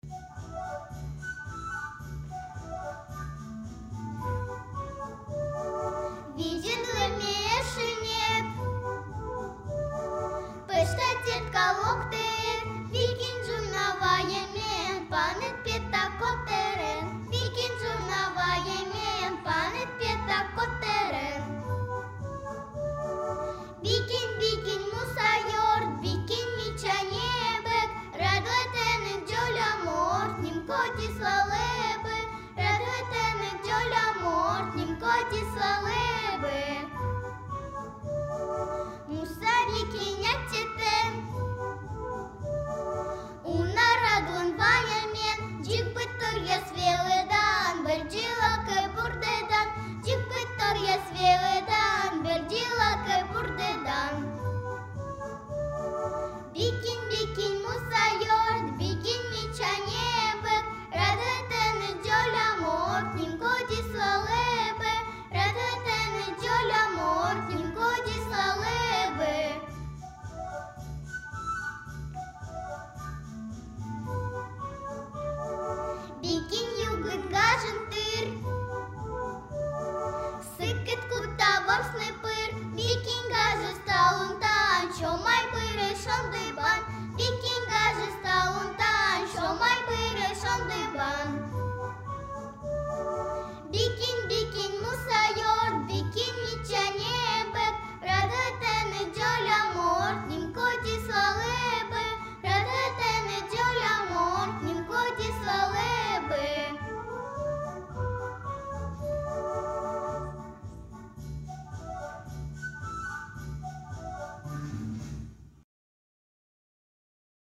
Сьыланкыв